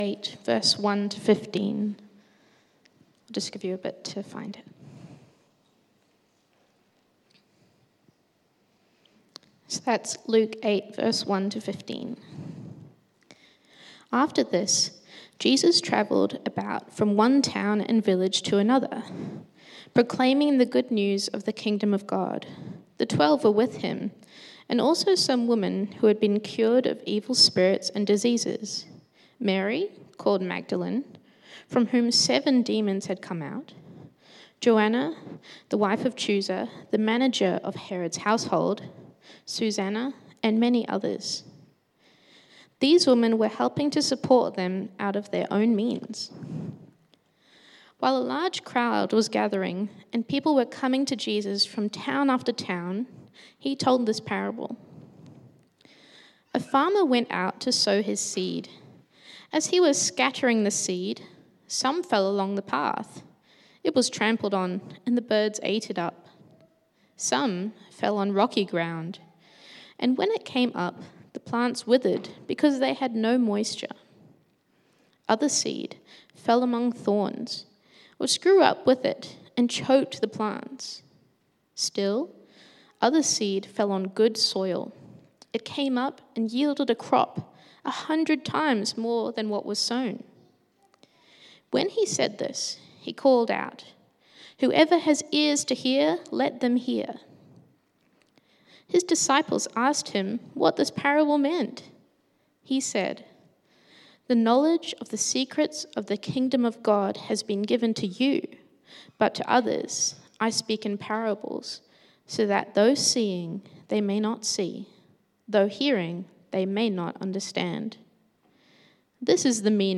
16 February 2025 Parable of the Sower Preacher